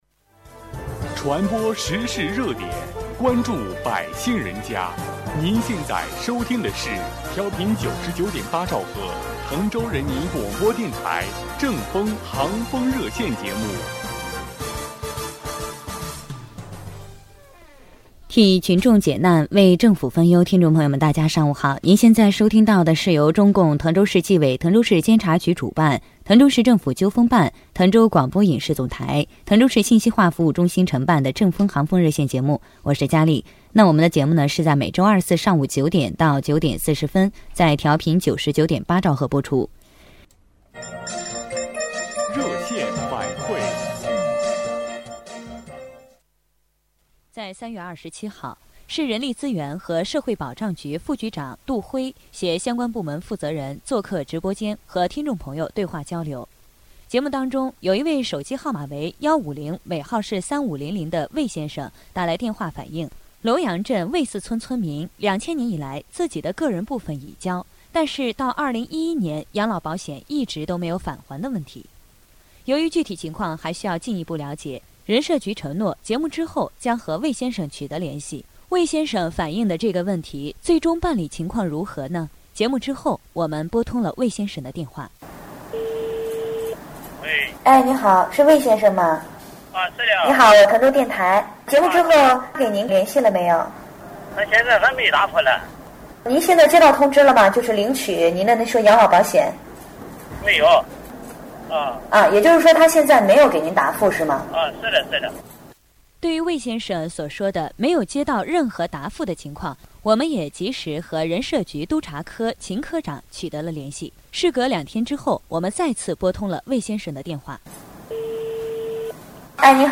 关注民生民情，倾听百姓声音 提升服务质量，助推经济发展 访谈音频下载 听众来电问题落实情况的汇报 1、要提前还贷，需到房管局办什么手续？